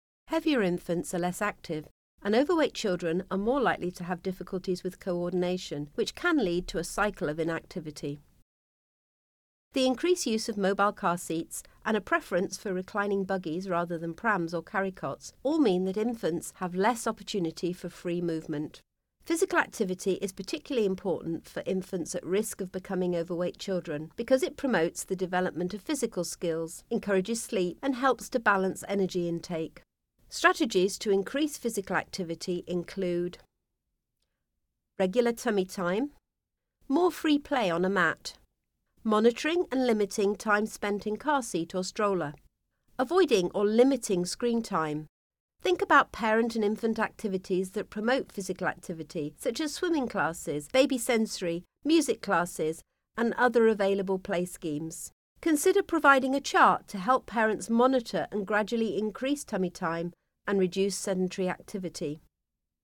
Previous Next Narration audio (MP3) Narration audio (OGG)